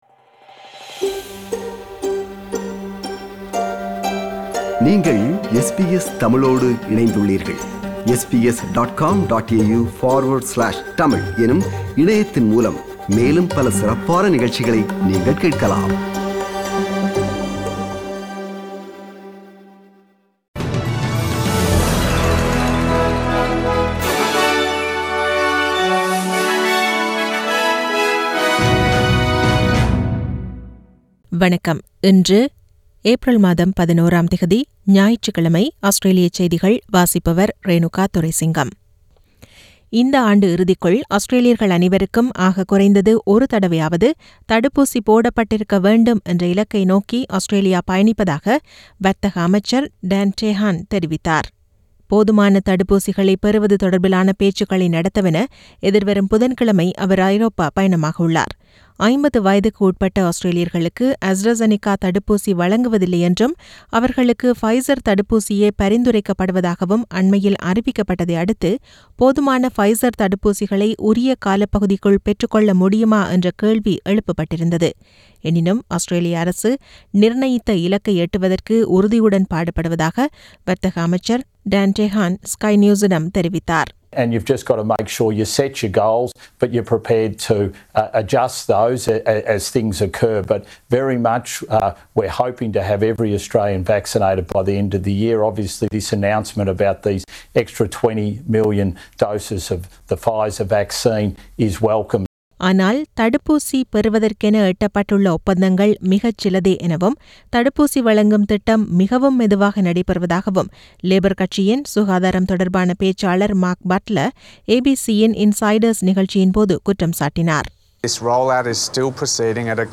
SBS தமிழ் ஒலிபரப்பின் இன்றைய (ஞாயிற்றுக்கிழமை 11/04/2021) ஆஸ்திரேலியா குறித்த செய்திகள்.